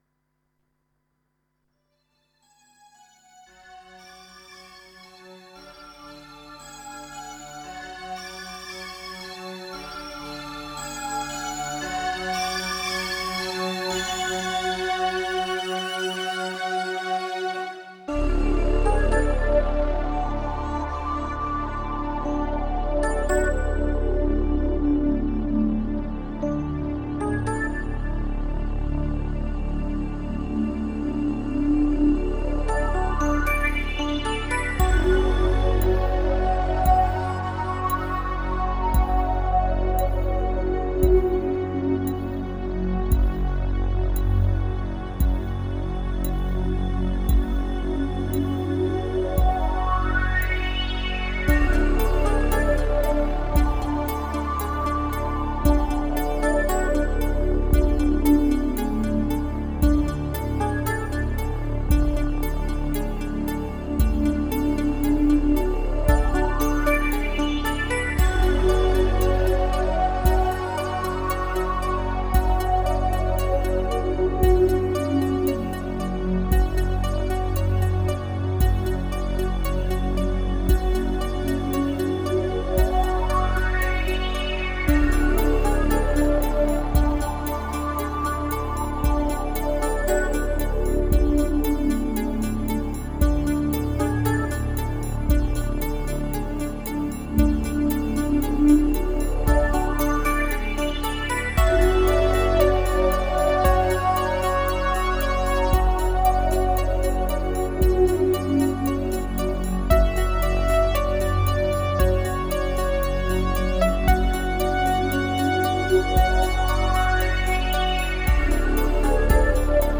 Single Mix